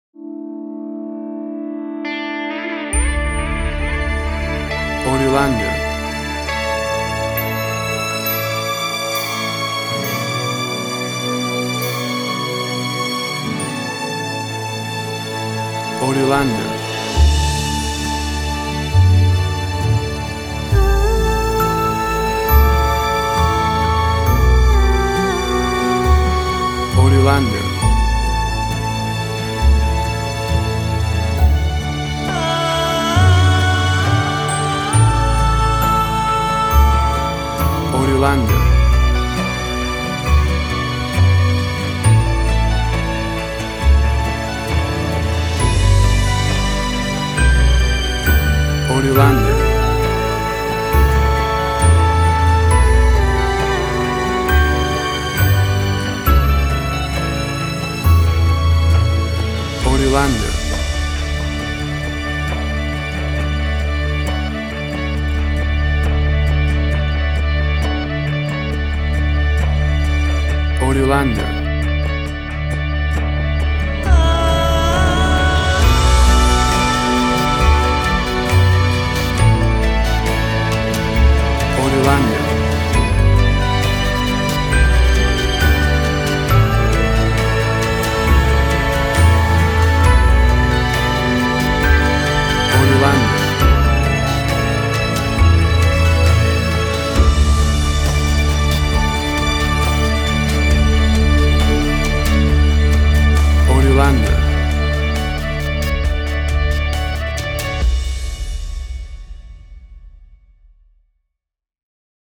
Tempo (BPM): 67